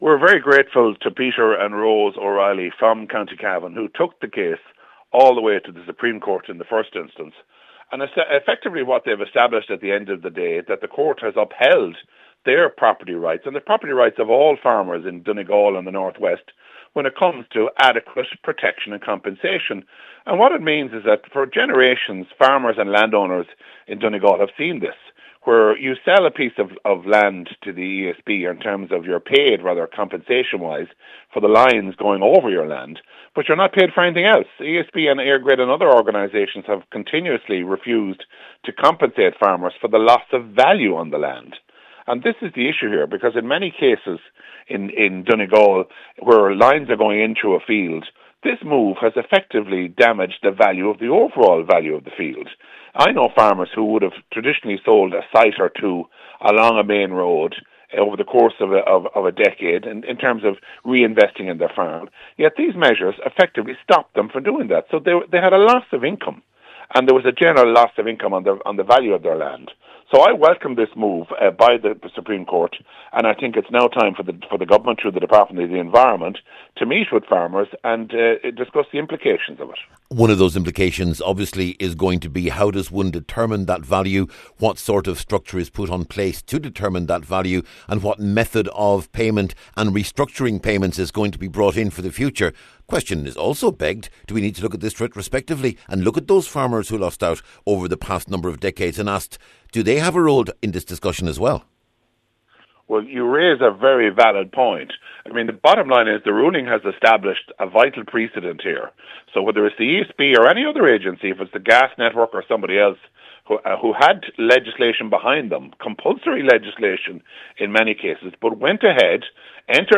Mr Mullooly told Highland Radio News this that this judgment could have wider implications, and the government must immediately consider what new legislation is necessary.